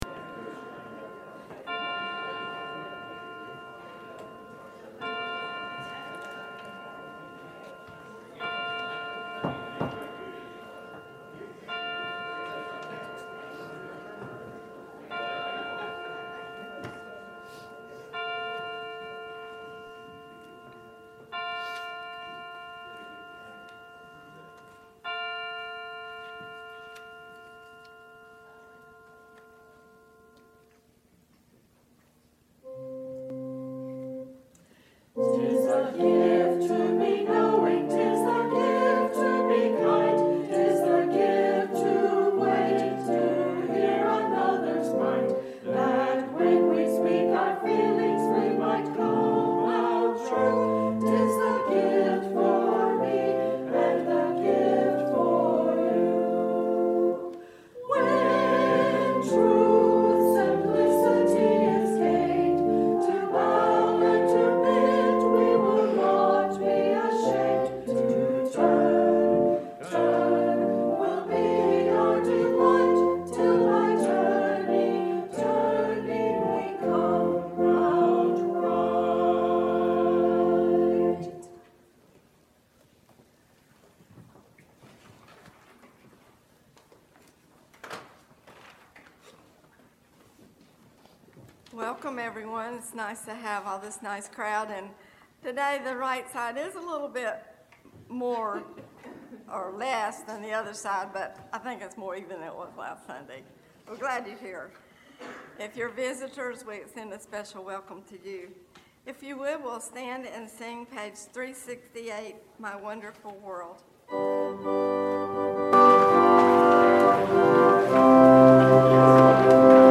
5 Service Type: Sunday Worship Topics